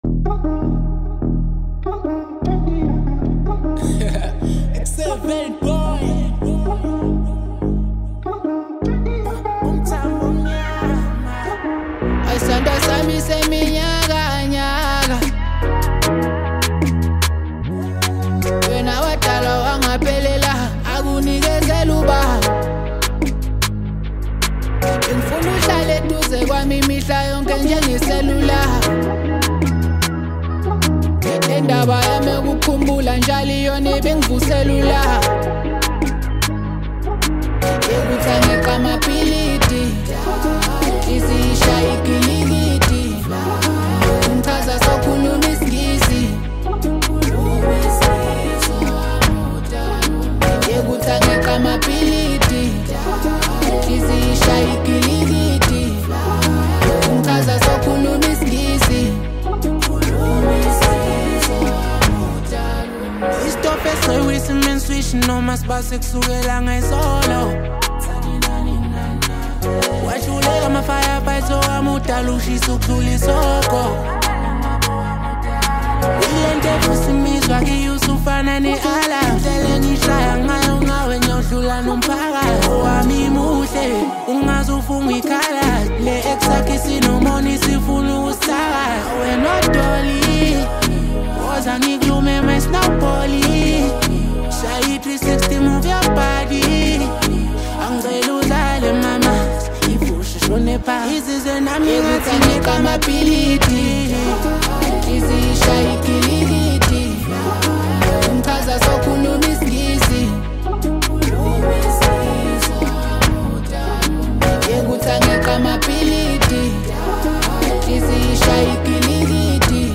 vibrant sound, strong energy, smooth delivery